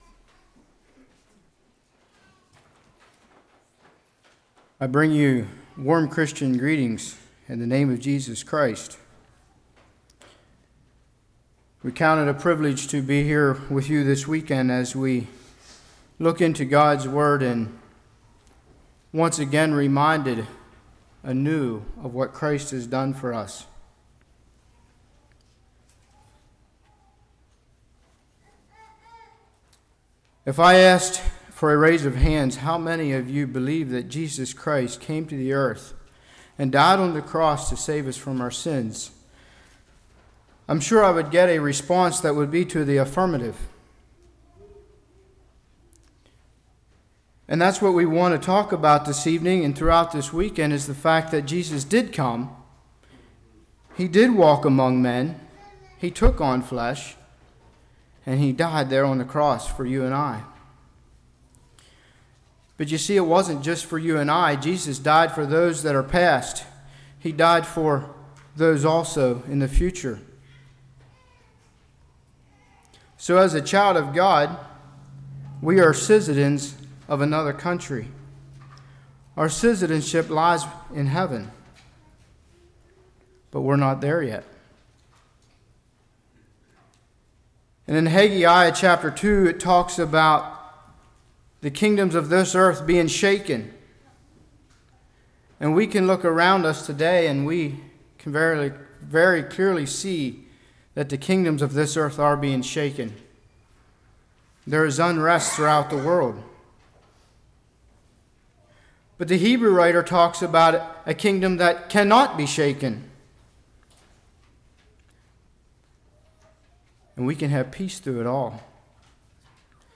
Fall Lovefeast 2013 Passage: 2 John 1-13 Service Type: Evening The True Way Teaching the Truth Love One Another « Passions of Christ Working Together